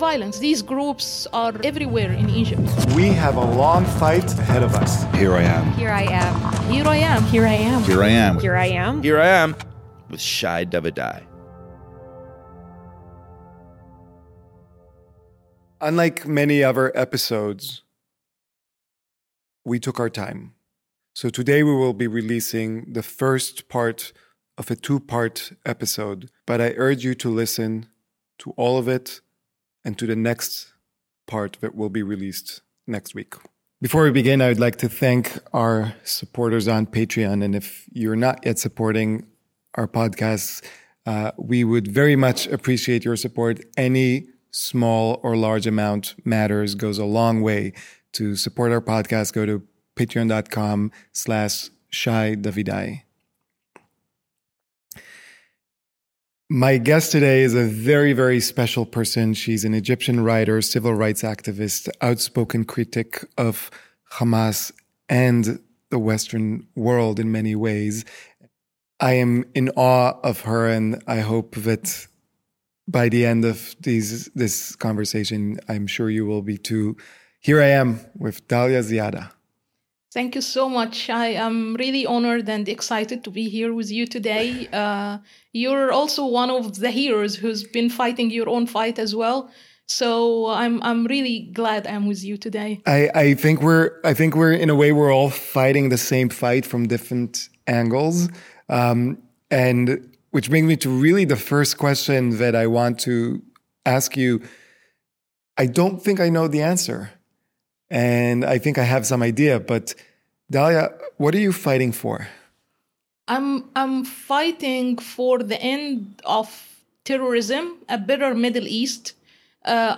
This conversation offers deep insights into the complexities of the Middle East and the courage it takes to advocate for change.